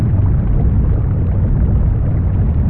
uWater1a.ogg